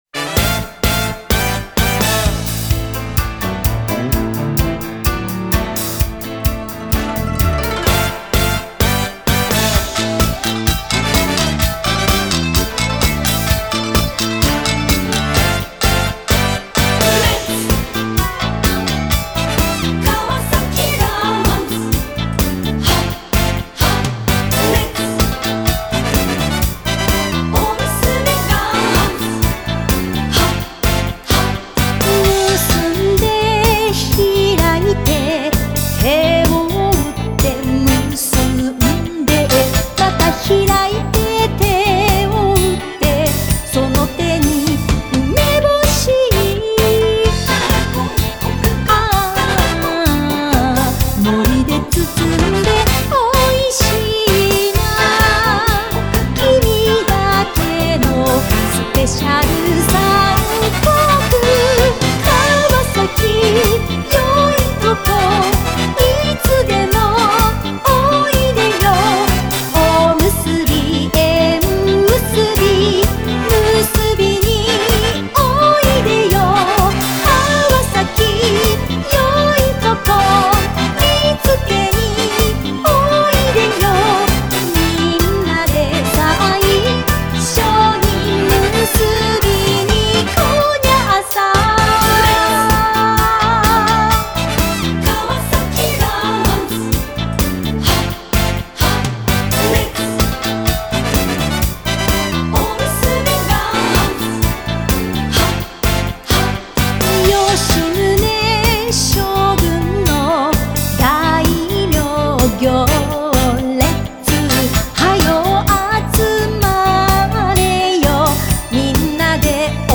おむすび音頭は軽快なリズムと楽しい歌詞！